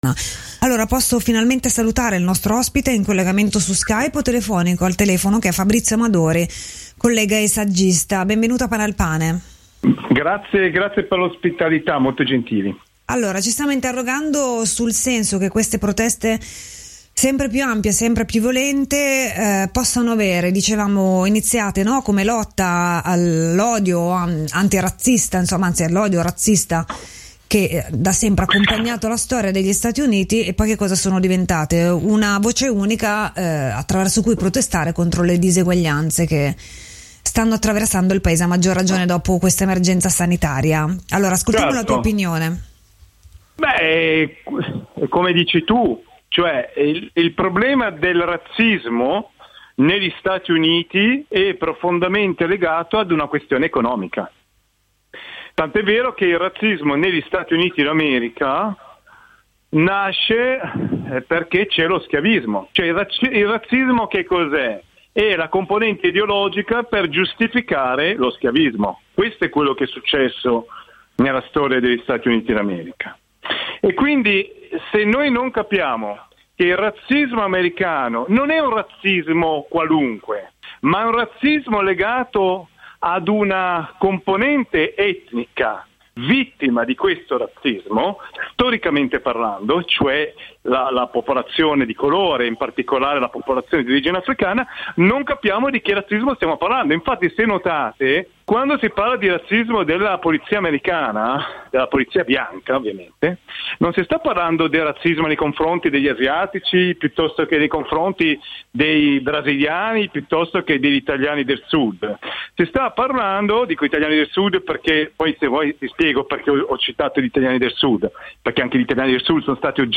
INTERVISTA A RADIO LOMBARDIA del 03/06/2020 SUL RAZZISMO AMERICANO DOPO LA MORTE DI GEORGE FLOYD